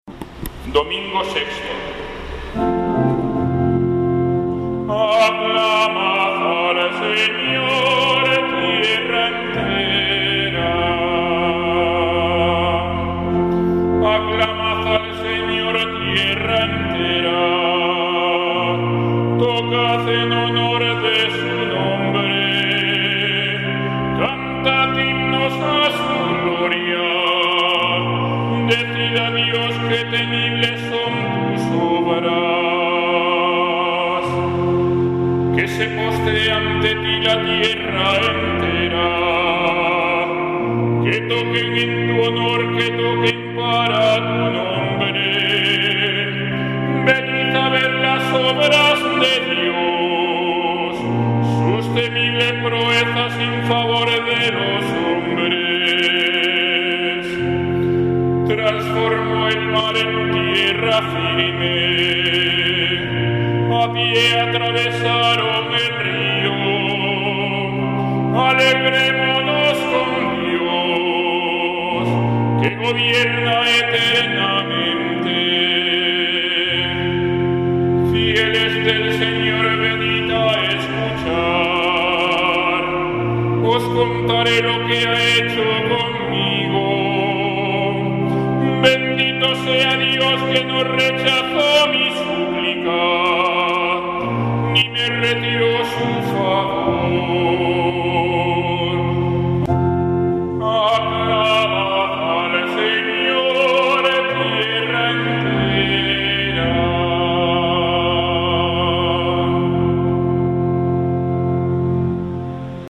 Salmo Responsorial 65/ 1-7; 16 y 20